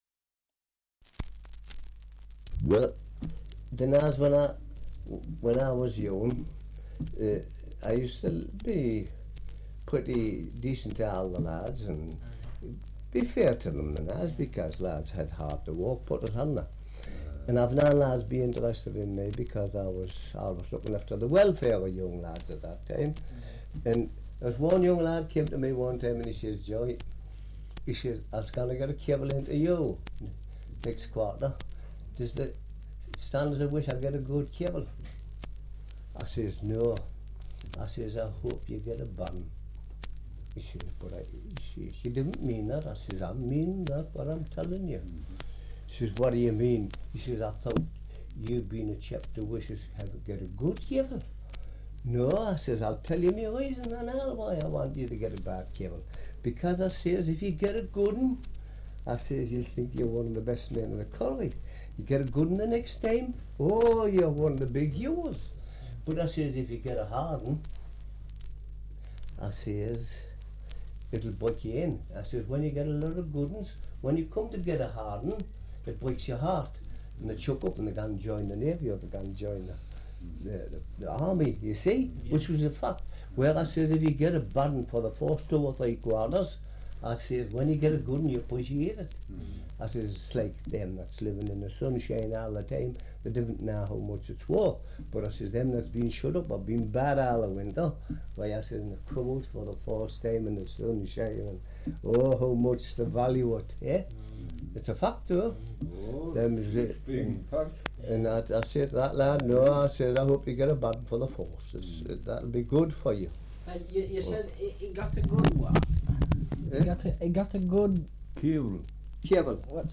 2 - Survey of English Dialects recording in Washington, Co. Durham
78 r.p.m., cellulose nitrate on aluminium